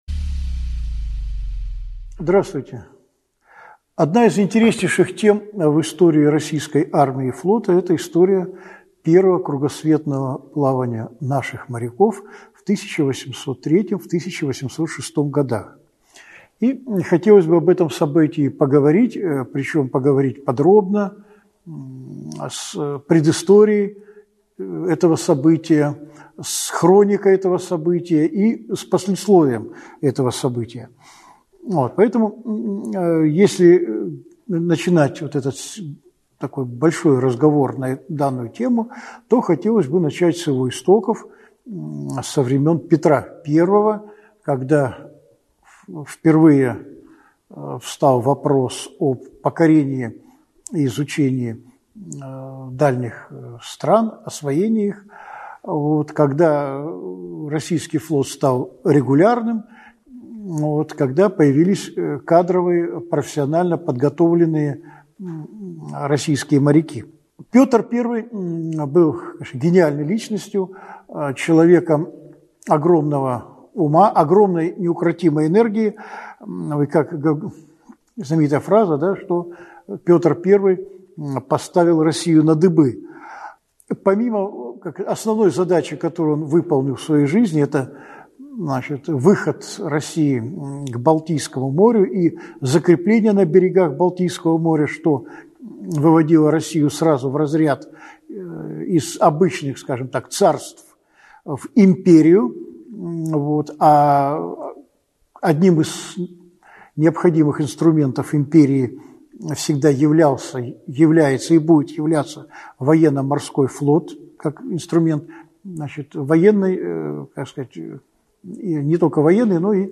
Аудиокнига Первая кругосветная экспедиция русского флота. Часть 1 | Библиотека аудиокниг